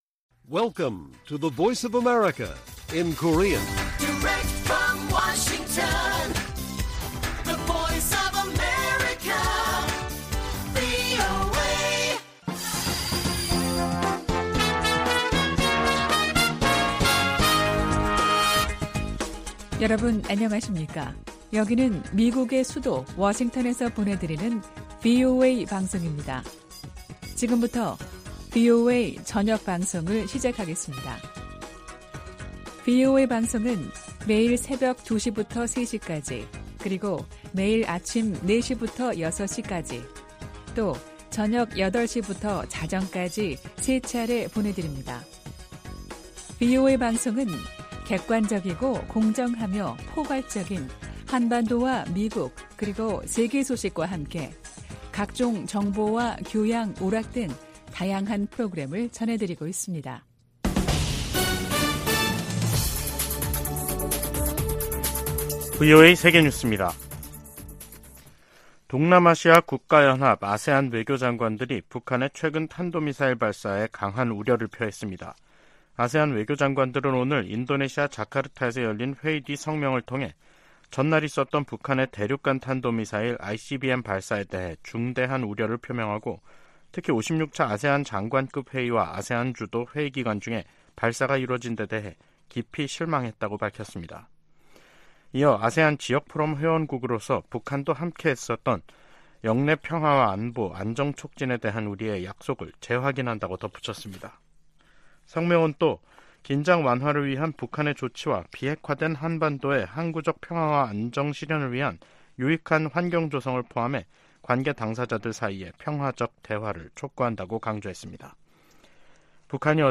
VOA 한국어 간판 뉴스 프로그램 '뉴스 투데이', 2023년 7월 13일 1부 방송입니다. 13일 북한 당국이 전날(12일) 발사한 탄도미사일이 고체연료 대륙간탄도미사일(ICBM) 화성-18호라고 밝혔습니다. 미국 정부와 의회, 유엔과 나토 사무총장, 한일 정상, 미한일 합참의장이 북한 정권의 장거리탄도미사일 발사를 강력히 규탄하며 대화에 나설 것을 촉구했습니다. 북한 주민들에게 자유와 진실의 목소리를 전해야 한다고 미국 의원들이 강조했습니다.